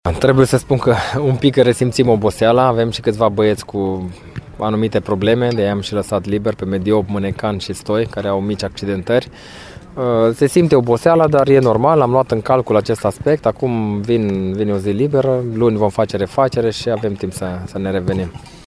Antrenorul Ripensiei, Paul Codrea, a admis că în acest joc s-a resimțit oboseala, după ce în cursul săptămânii echipa a mai disputat două teste, cu CSM Școlar Reșița (0-0) și ACS Poli (1-4).